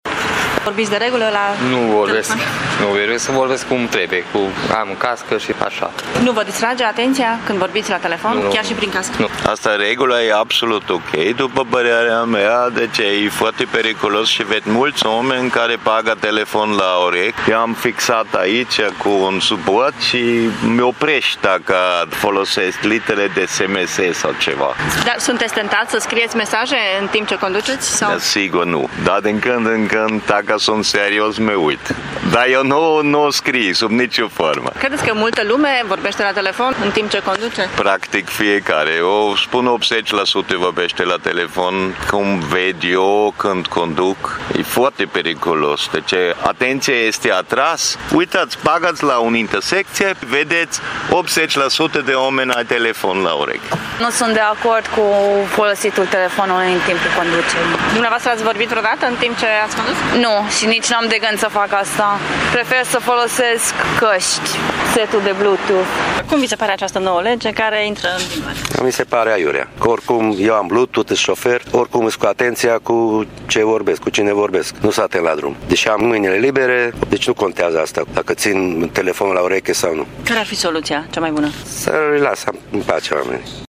Mulți șoferi târgumureșeni știu ce-i așteaptă iar părerile lor sunt împărțite: